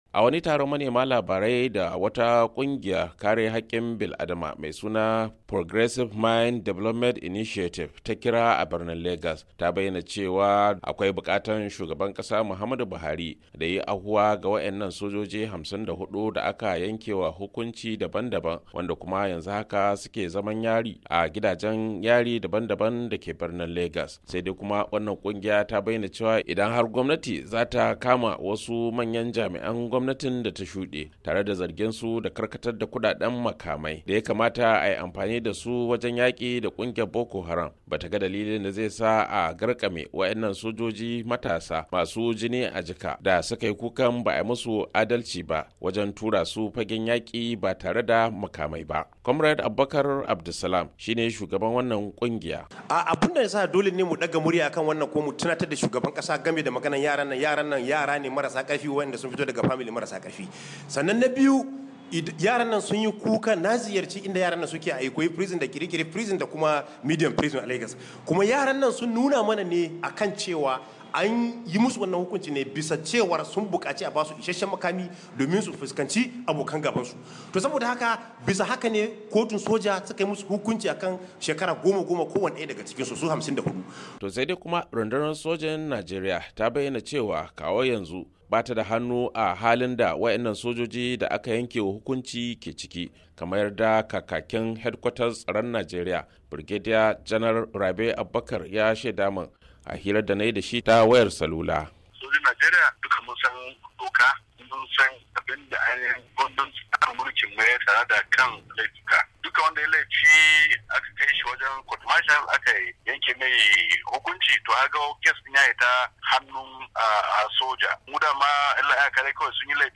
A wani taron manema labarai da kungiyar kare hakkin bil-Adama mai suna Progressive Mind Development Initiative tayi a Lagos.